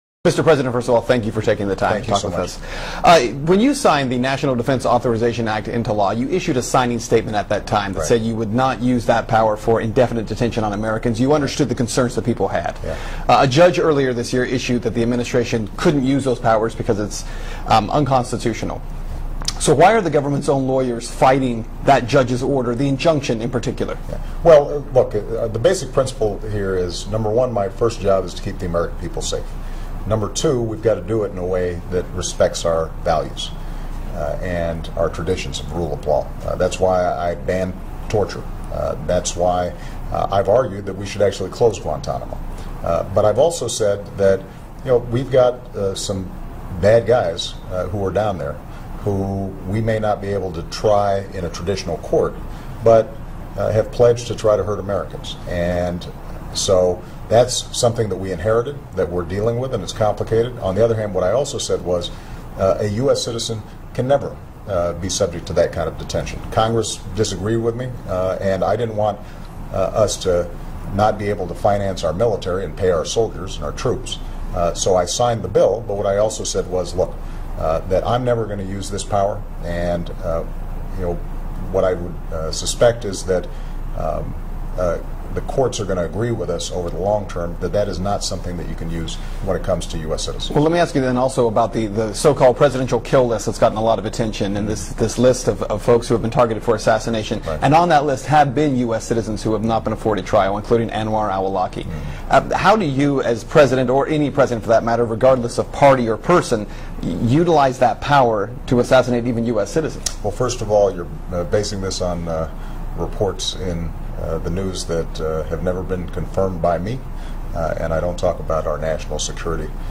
Ben Swann interviews President Obama NDAA Kill List Syria Afghanistan